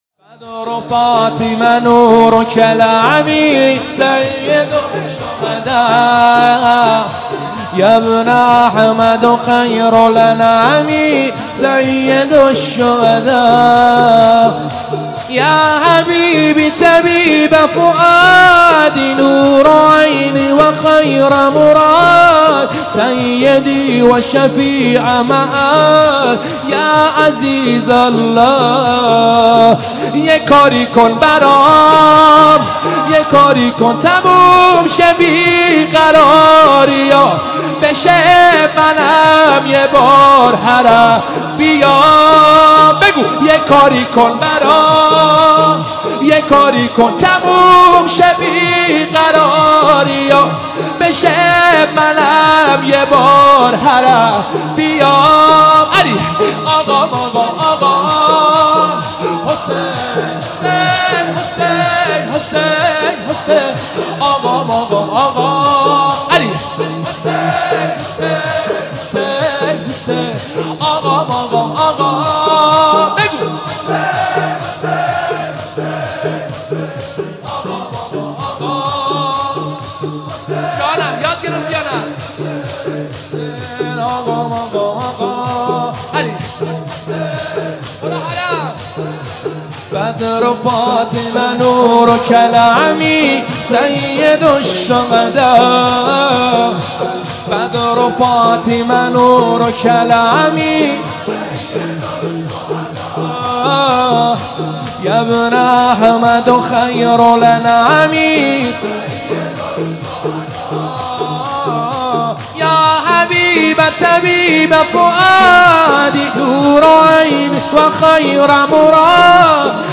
مداحی شب اول محرم